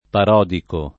[ par 0 diko ]